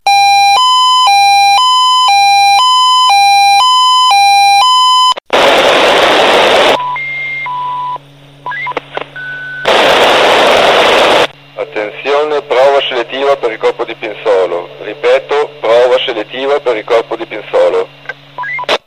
CLICCA PER SENTIRE COME VENGONO ALLERTATI I NOSTRI VIGILI
selettiva.mp3